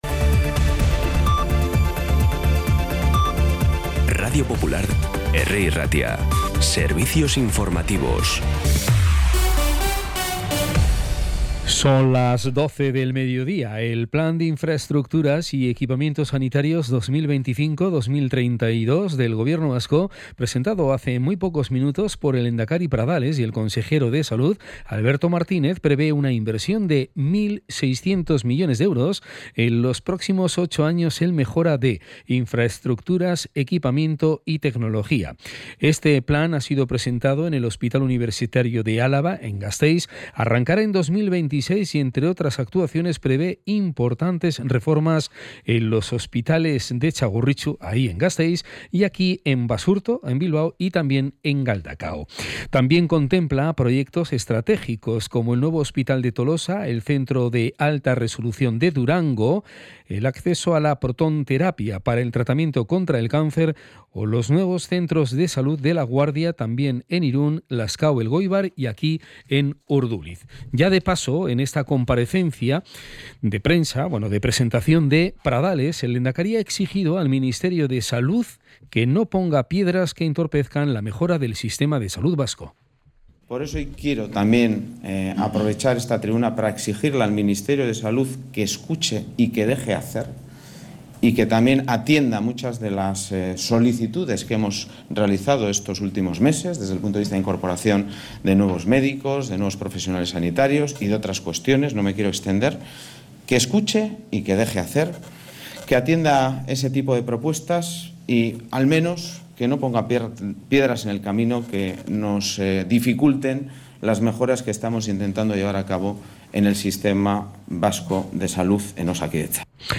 Las noticias de Bilbao y Bizkaia del 17 de diciembre a las 12
Los titulares actualizados con las voces del día. Bilbao, Bizkaia, comarcas, política, sociedad, cultura, sucesos, información de servicio público.